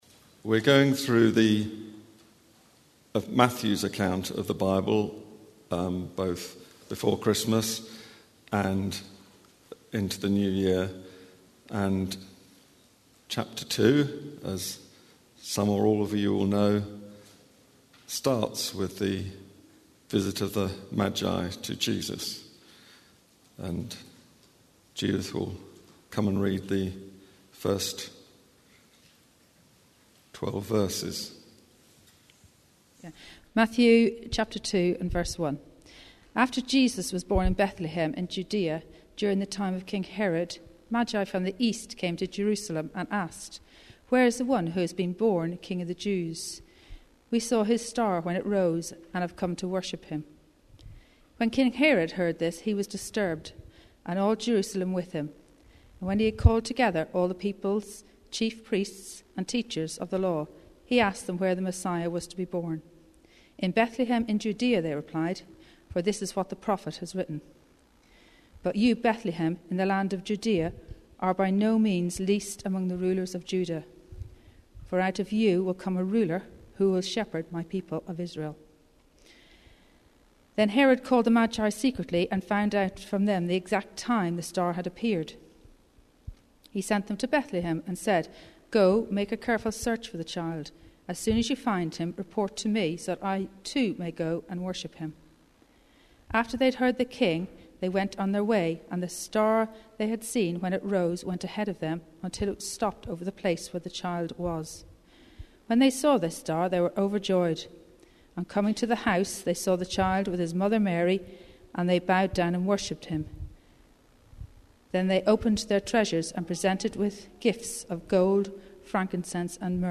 Thanks for joining us for our first service of 2022!